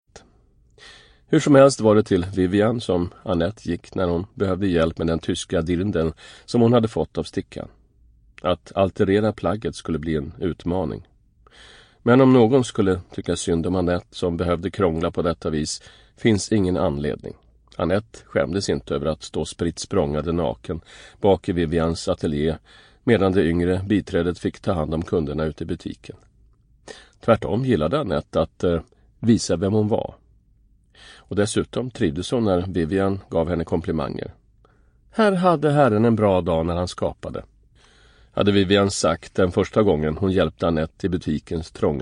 Statsministern som försvann / Ljudbok